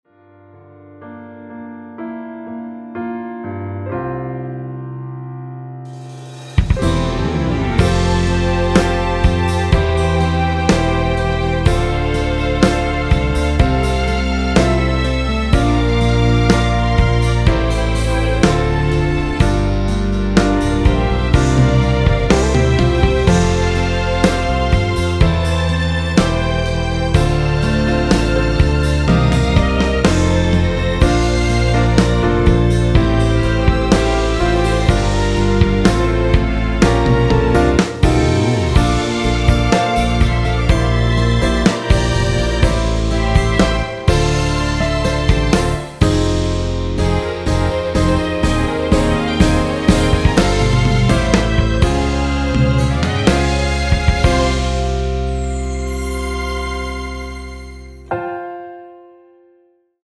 원키에서(+4)올린 1절후 후렴으로 진행 되는 MR입니다.(본문 가사 확인)
◈ 곡명 옆 (-1)은 반음 내림, (+1)은 반음 올림 입니다.
앞부분30초, 뒷부분30초씩 편집해서 올려 드리고 있습니다.
중간에 음이 끈어지고 다시 나오는 이유는